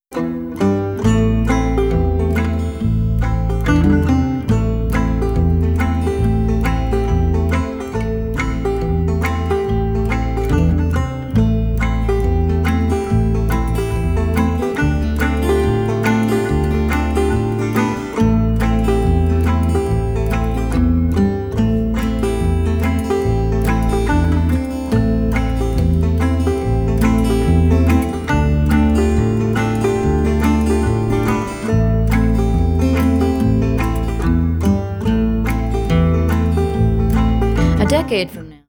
(haunting mountain groove)